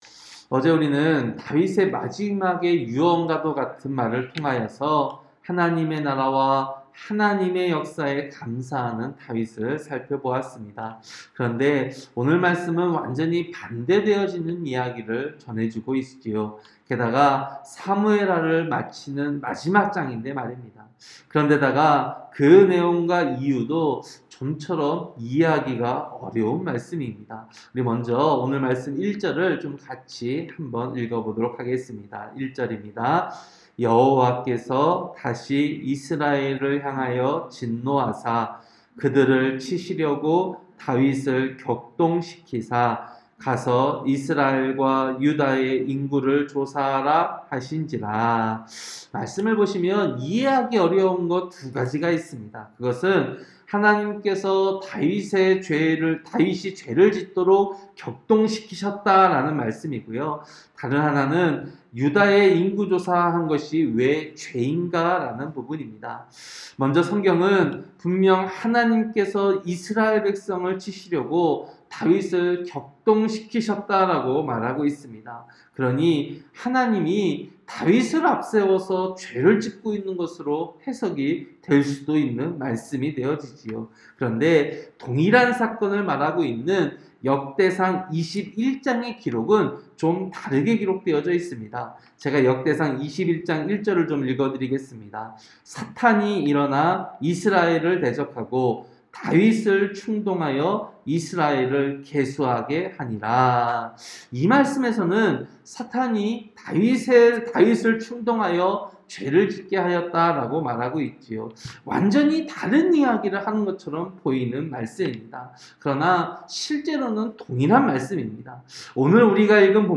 새벽설교-사무엘하 24장